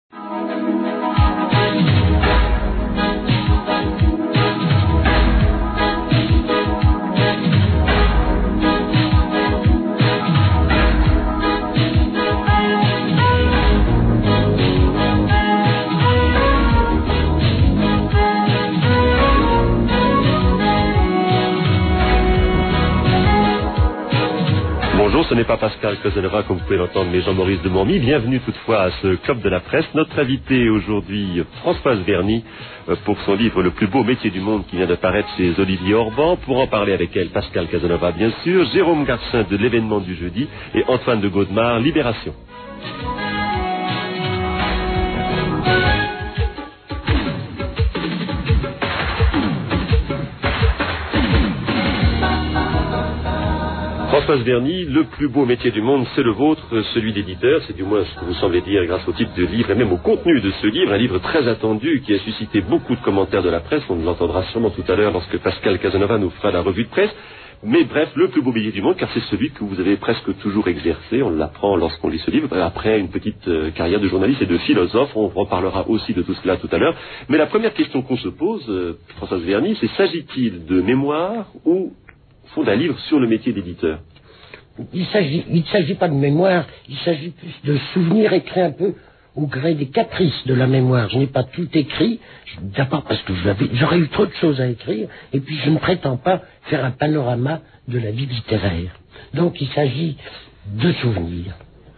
Oh la la ça fait vraiment tout drôle d'entendre l'indicatif du Panorama.
Françoise Verny a en face d'elle des critiques courtois qui ne la ménagent pas.
S'il vous plaît, jetez-moi auw orties pour cette réflexion, mais je trouve que Françoise Verny a des accents de Daffy Duck ("j'aurais eu trop de choses à écriiire").